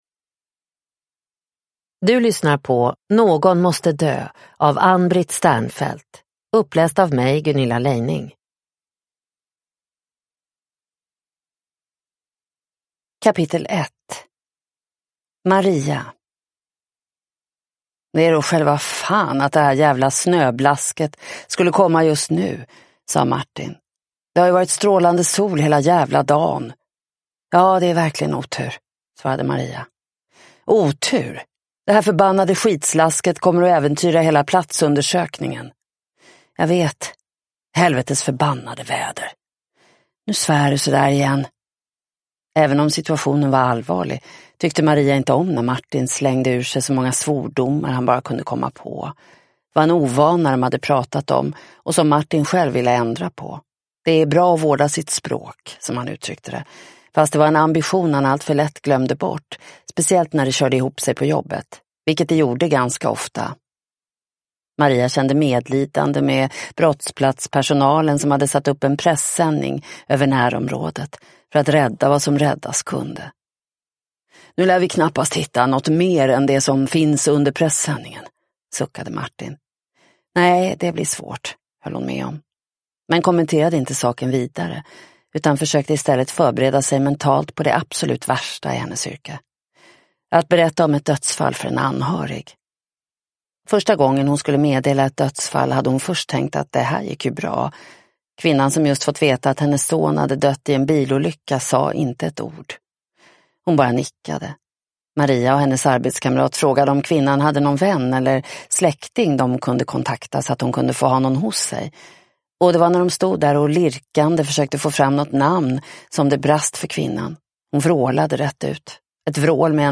Någon måste dö (ljudbok) av Ann-Britt Sternfeldt